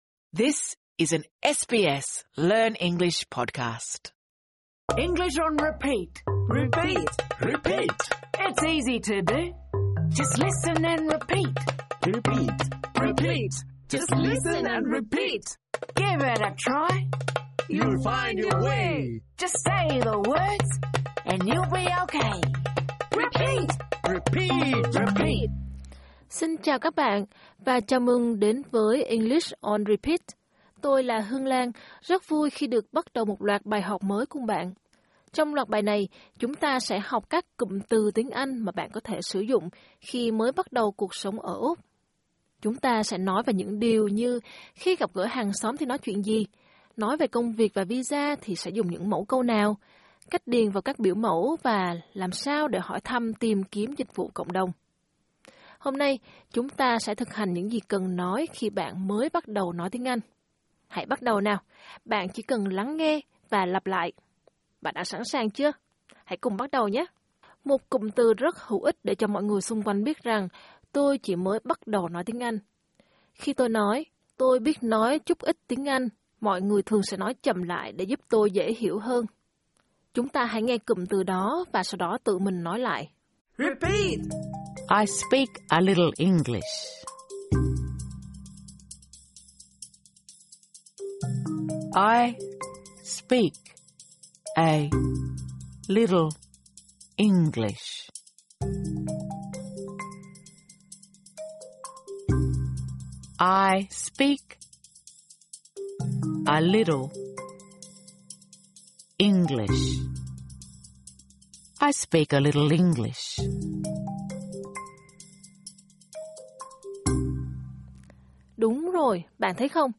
Bài học này được thiết kế dành cho những người đang ở trình độ mới bắt đầu. Trong tập này, chúng ta hãy thực hành nói những mẫu câu sau đây I speak a little English.